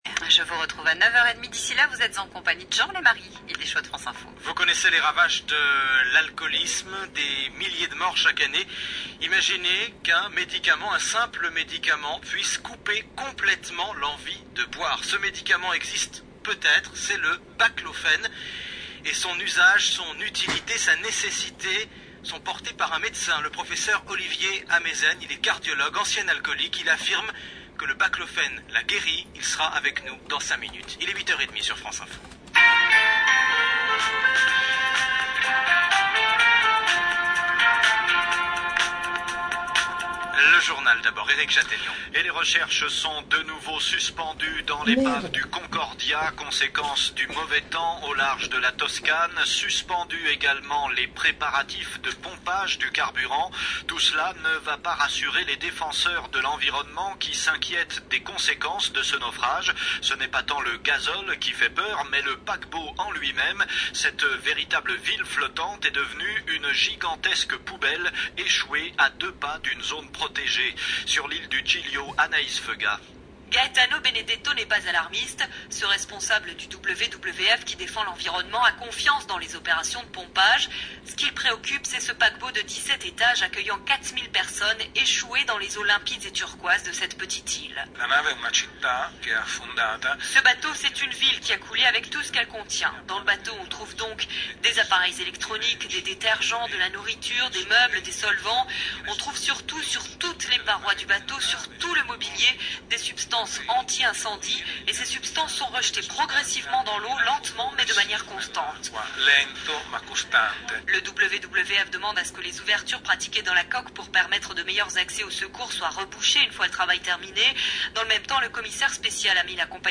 Baclofène – Interview du Professeur Ameisen